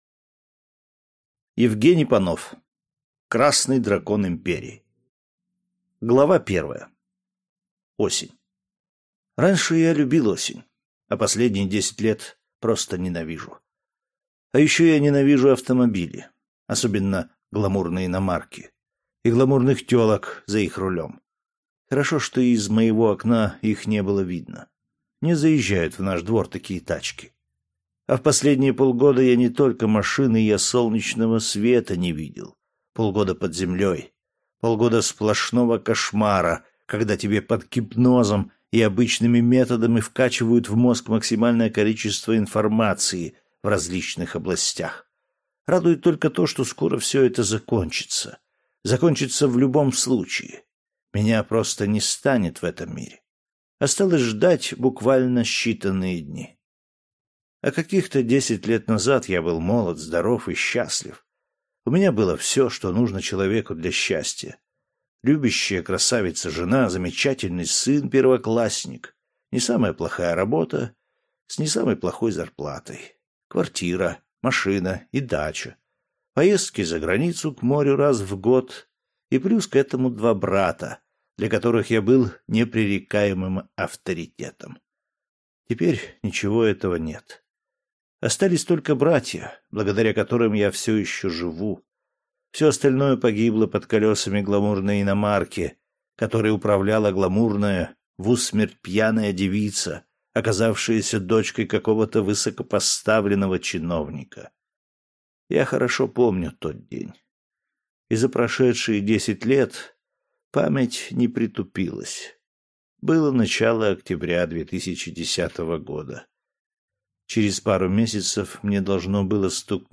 Аудиокнига Красный Дракон Империи | Библиотека аудиокниг